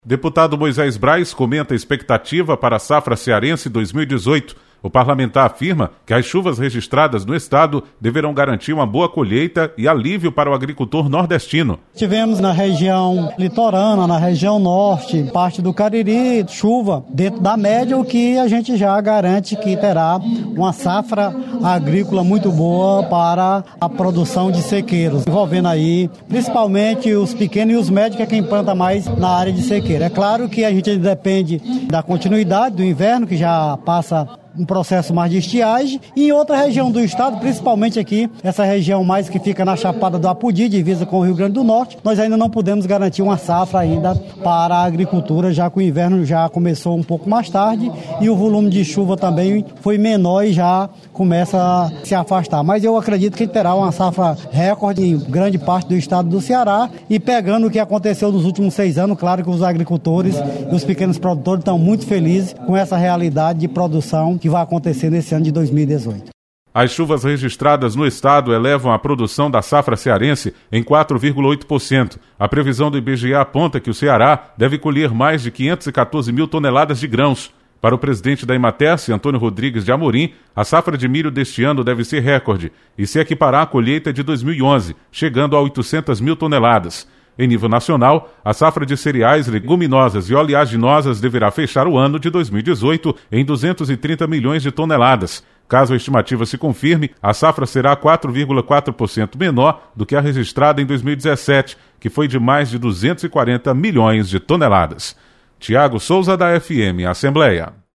Deputado Moisés Braz comenta expectativa  para a safra  de 2018.